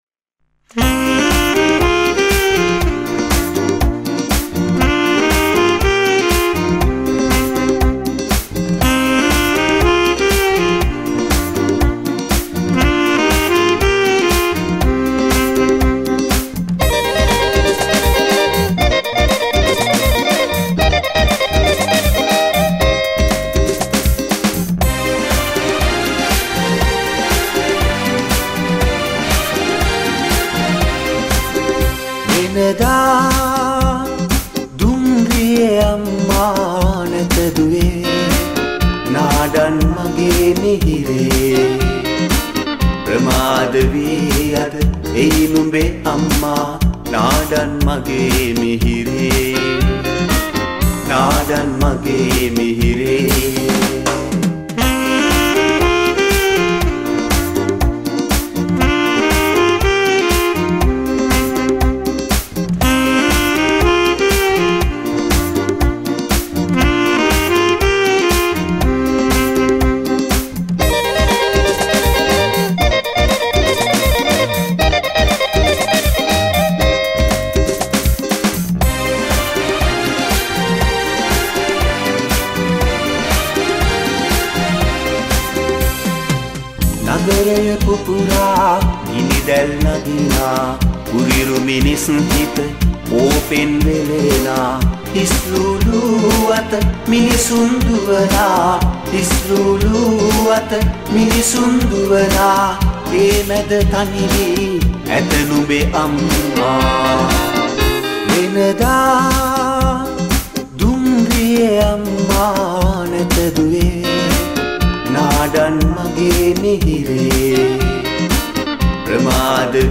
saxapone